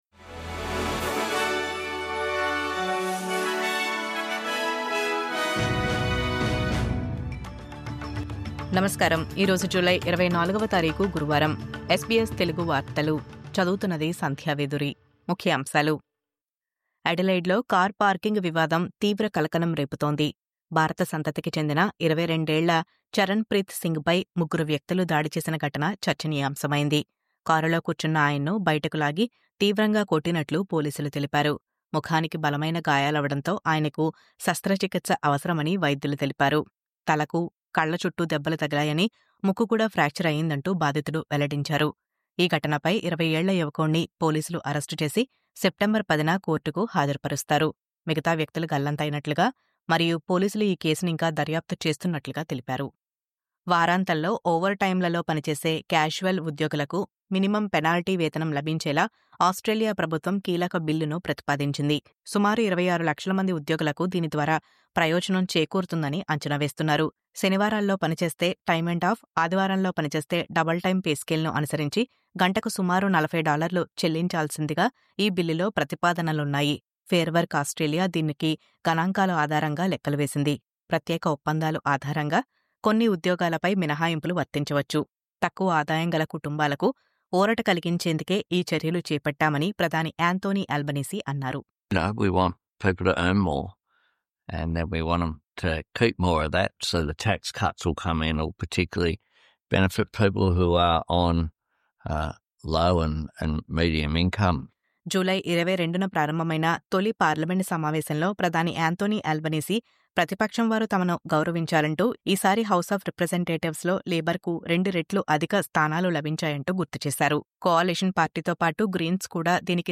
ఈ రోజు ముఖ్యాంశాలు.